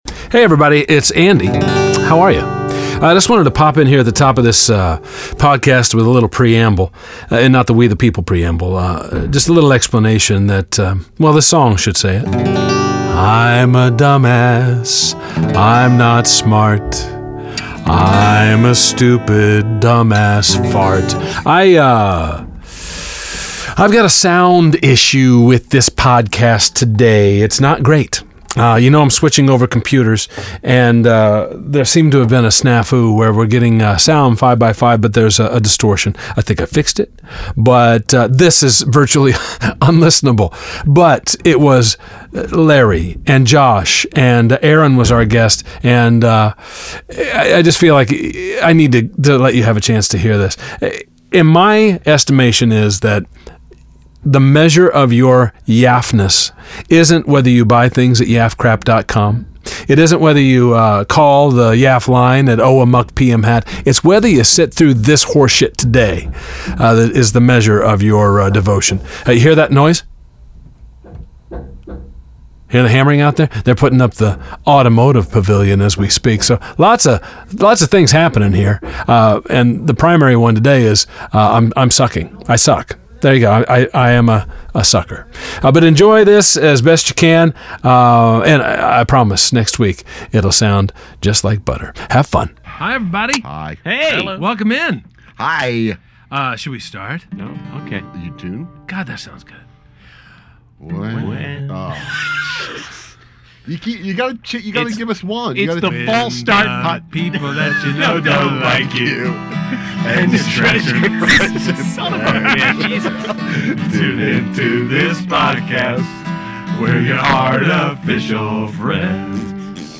Here we go again -- we've experienced technical difficulties with our switchover to a new recording interface. Somehow, somewhere, the signal got distorted to hell and back.